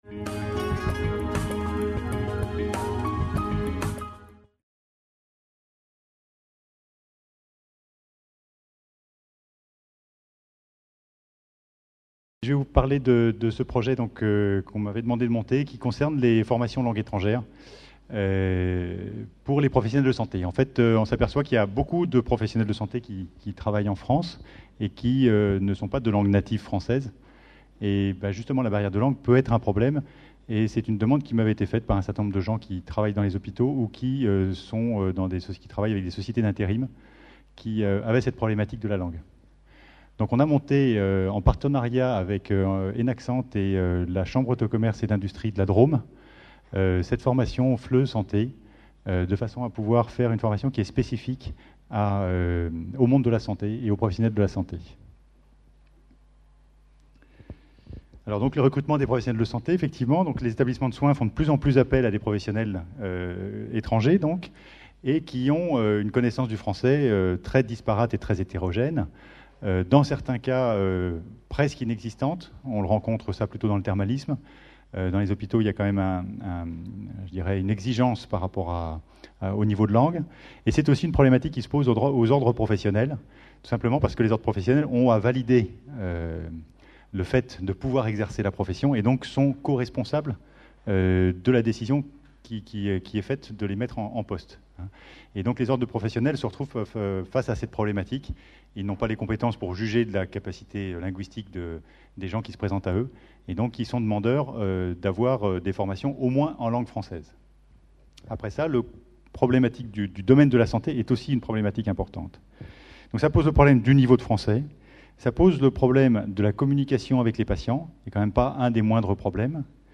FORMATIC - Paris 2011 : Un dispositif de formation au FLE pour les professionnels de santé d’origine étrangère. Conférence enregistrée lors du congrès international FORMATIC PARIS 2011. Atelier TIC et pratiques innovantes au service de la formation des professionnels de la santé.